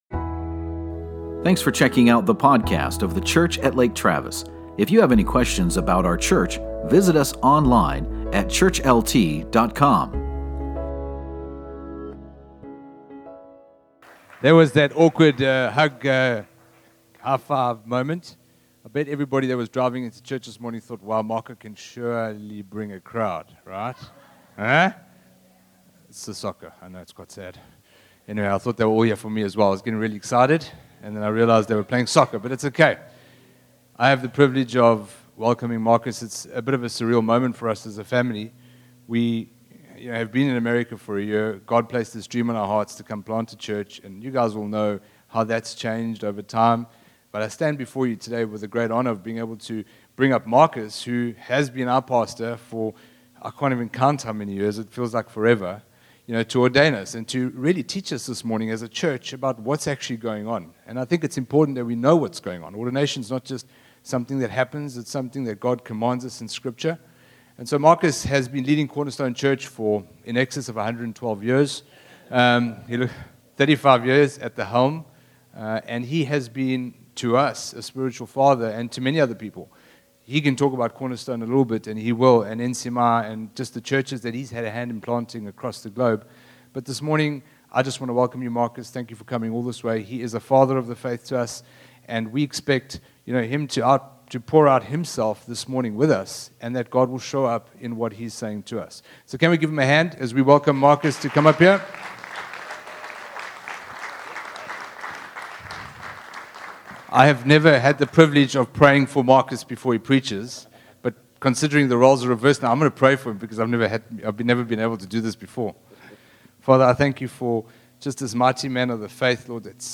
Ordination Celebration - Hope Rock Church